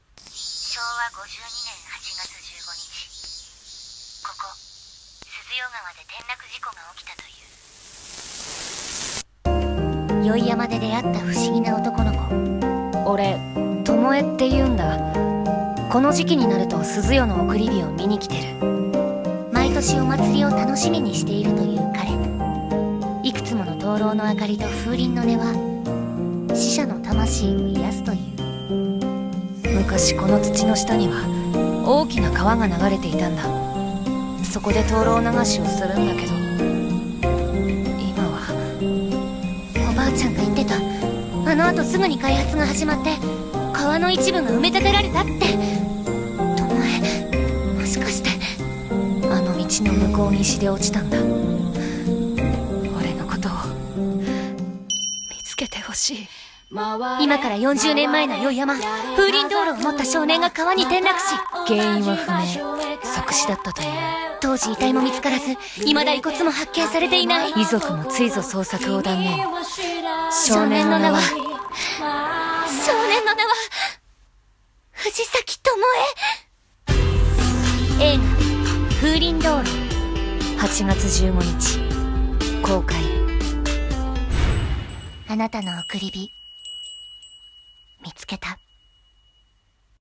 CM風声劇「風鈴燈籠」お手本